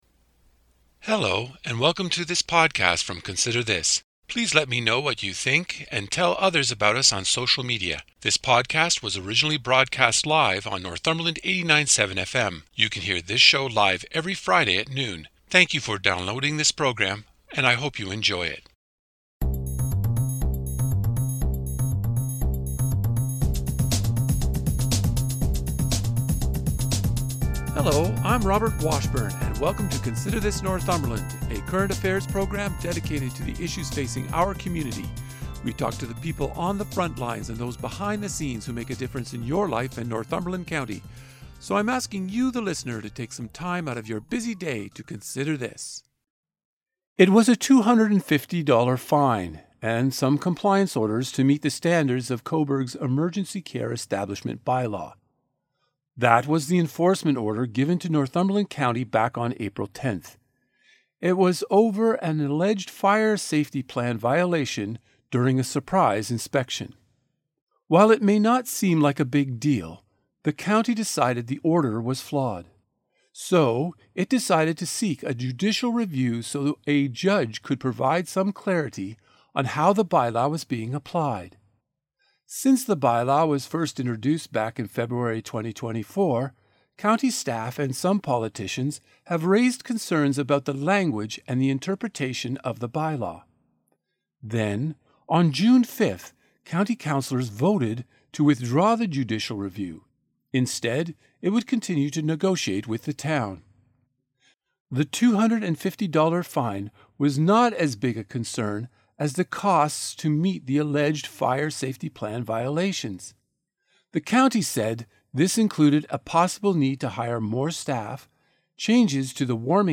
In this interview, you will hear Northumberland County Warden Brian Ostrander explain the county’s actions in pursuing a judicial review and, ultimately, to withdraw.